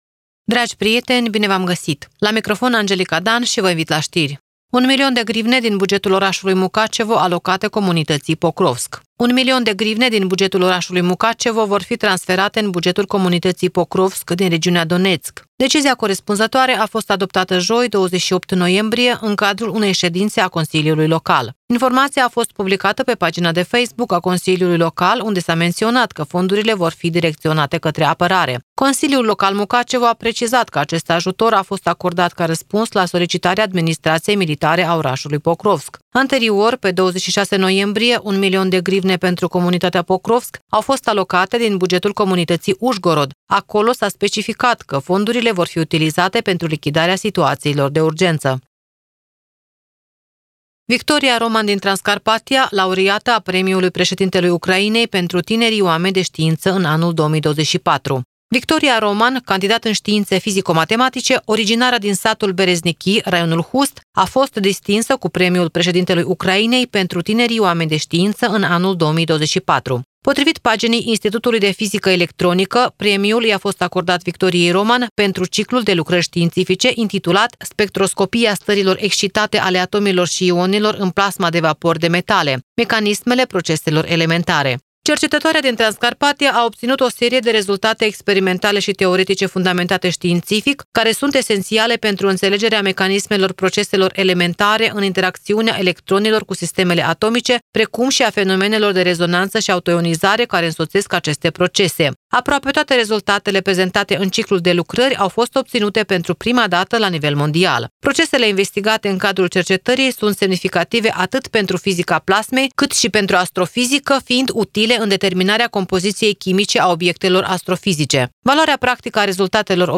Știri de la Radio Ujgorod.